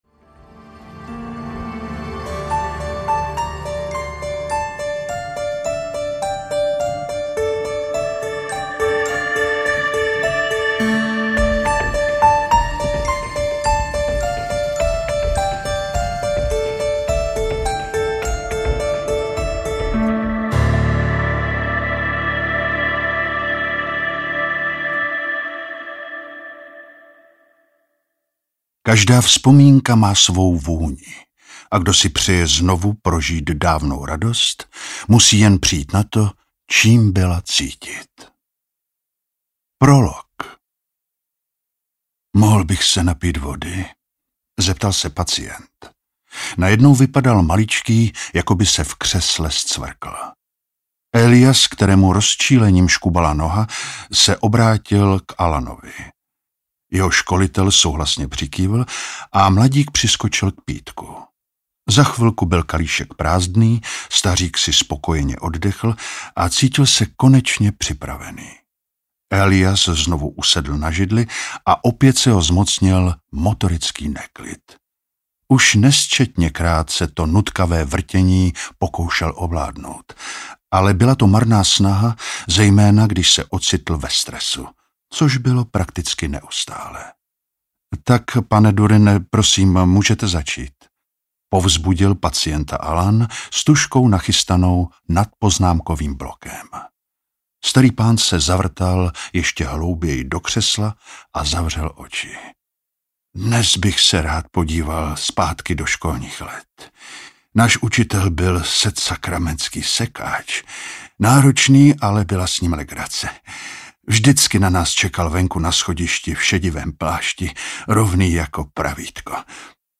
Esence audiokniha
Ukázka z knihy